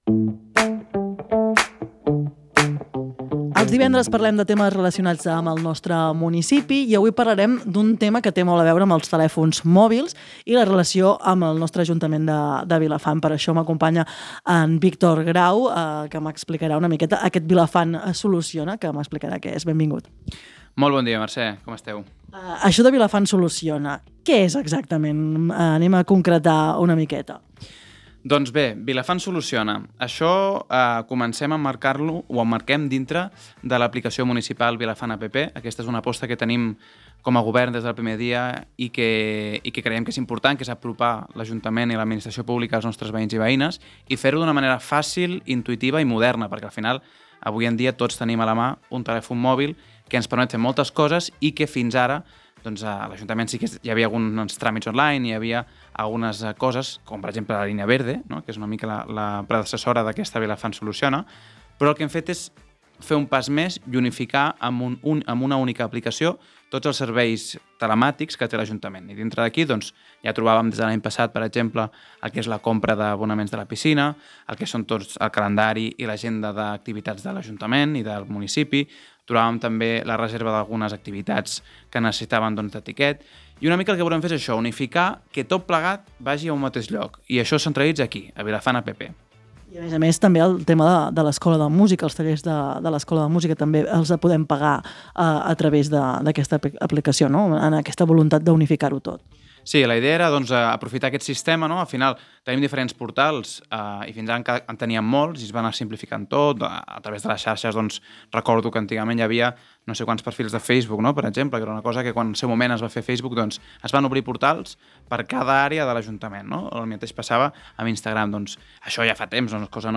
Durant la conversa, s’ha explicat com funciona aquesta eina, pensada per facilitar que la ciutadania pugui comunicar incidències de manera ràpida i directa, millorant així la comunicació amb l’Ajuntament de Vilafant i la resolució de problemes al municipi.